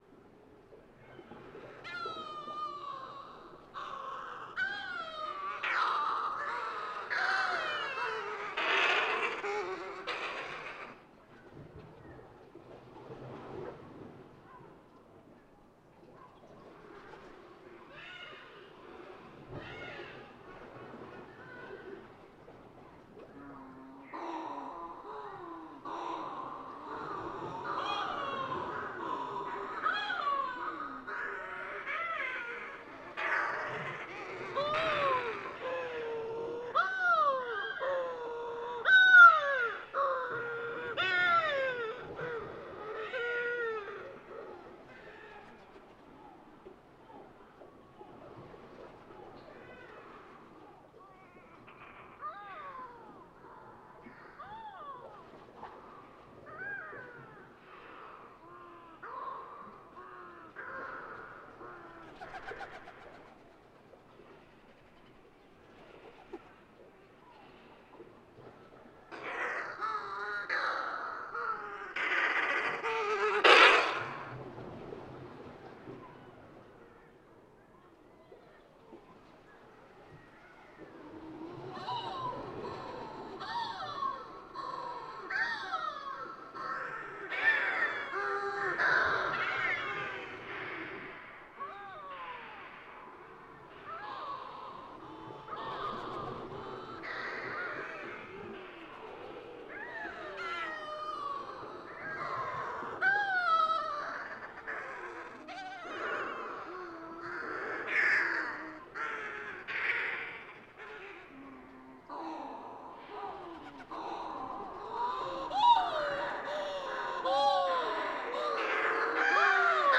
Balearics were hurtling past only a couple of metres above me.
1-50-Balearic-Shearwater.wav